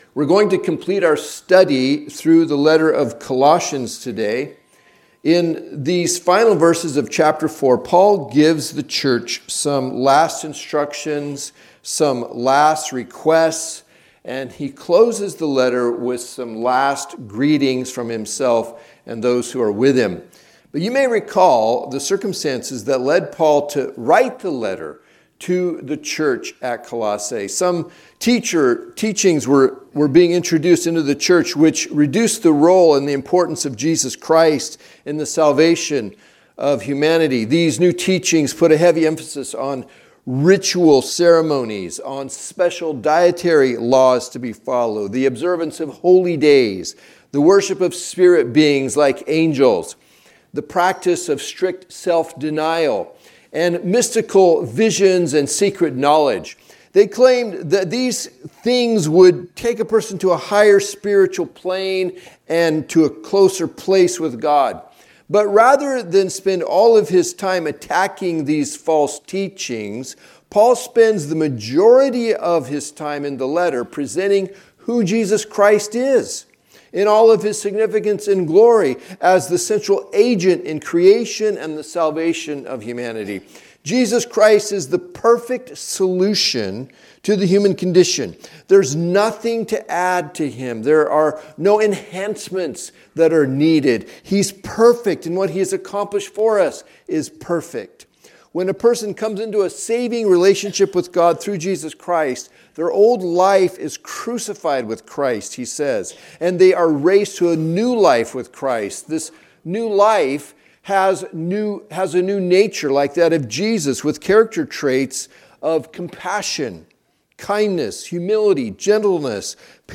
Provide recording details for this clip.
Recordings of the teaching from the Sunday morning worship service at Touchstone Christian Fellowship. Tune in each week as we move through the Bible in a way that is both relevant and challenging.